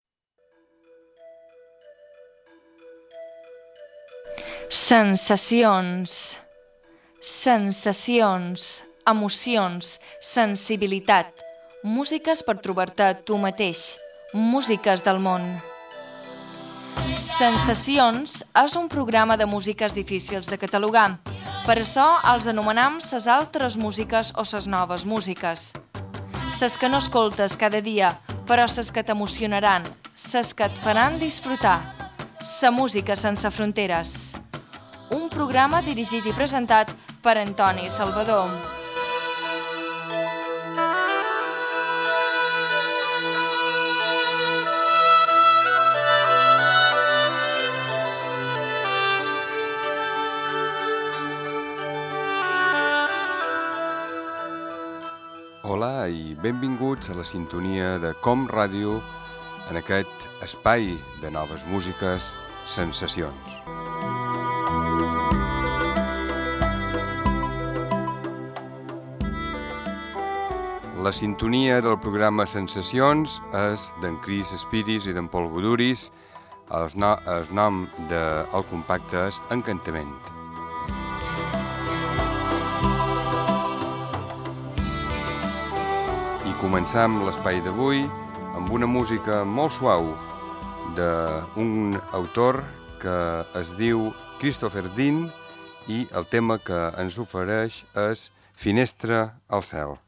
Careta del programa i presentació inicial del programa de noves músiques
Musical
FM